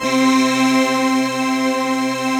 Index of /90_sSampleCDs/Optical Media International - Sonic Images Library/SI1_Breath Choir/SI1_BreathMellow